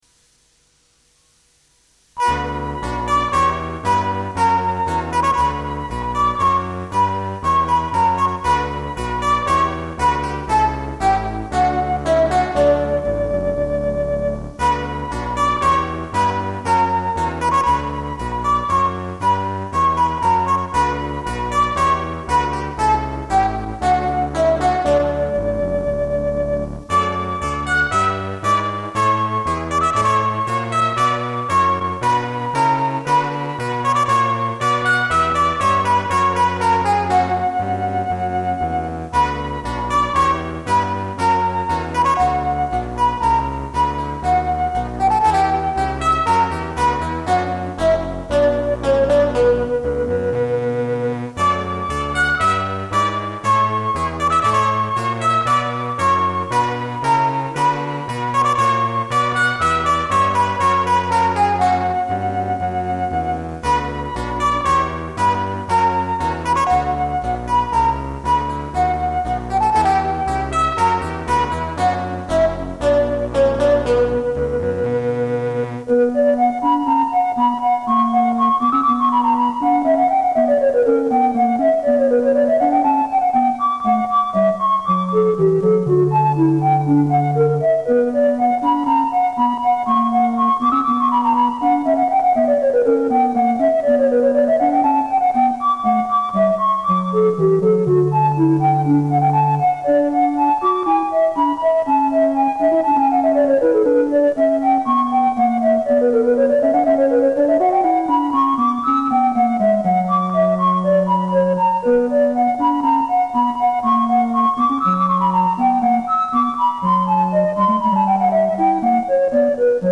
アナログ音源特有の暖かい音がします。
AR(Attack,Release専用エンベロープジェネレータ）でパルス幅が変調できたので、琴のような音も出せました